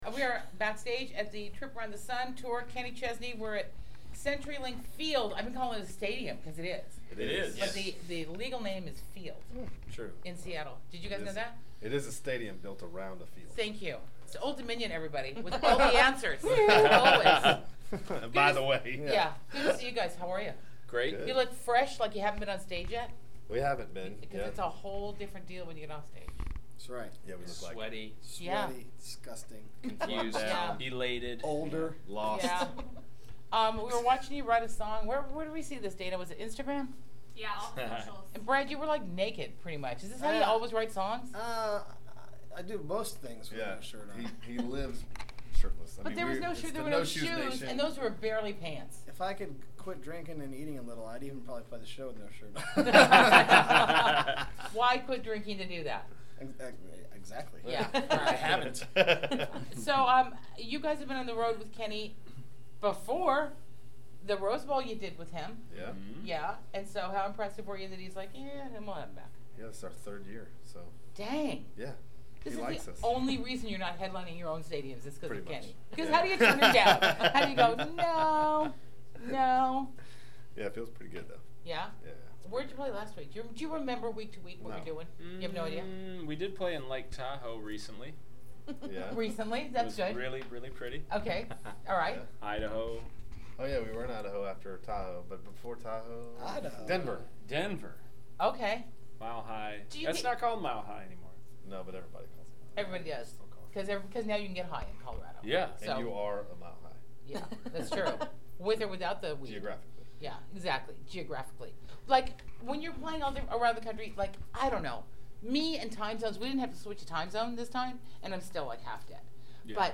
Interviews Old Dominion